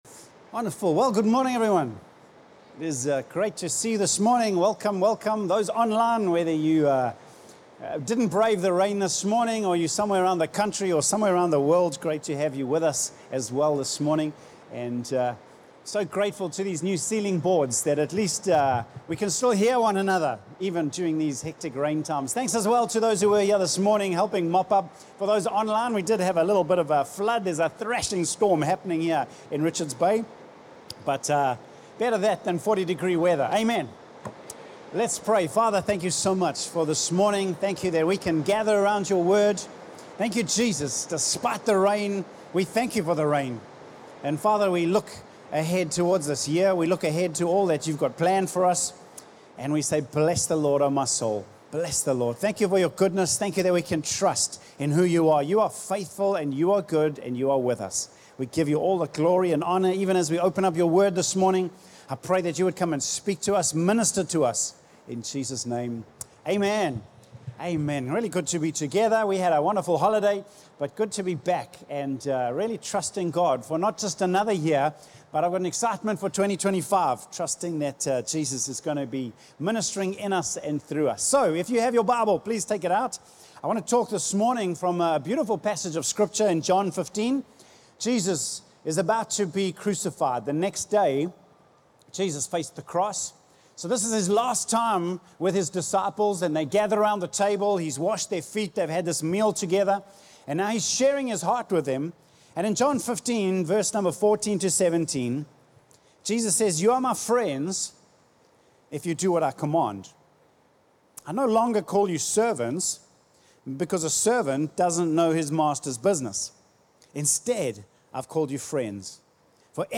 Live on September 15, 2024 Message